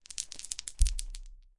描述：这是模拟领狗2.0的音频效果
Tag: 项圈 小狗